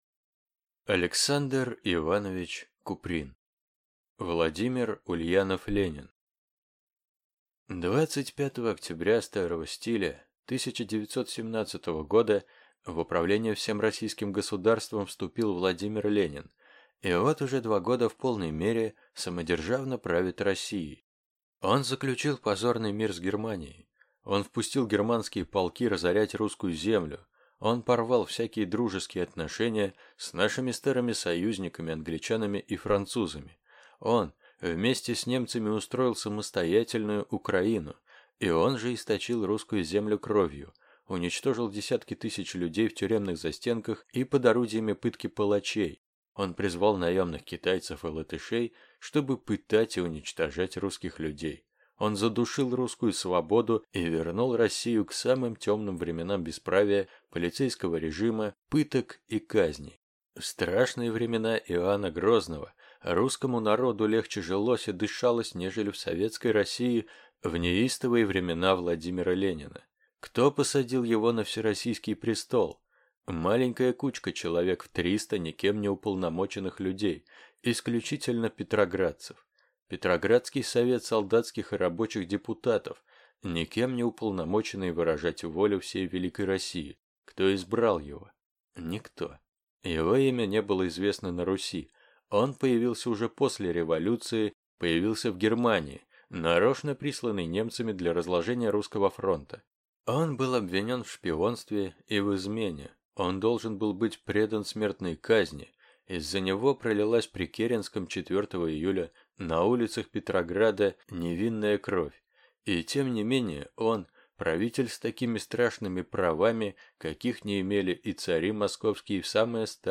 Аудиокнига Владимир Ульянов-Ленин | Библиотека аудиокниг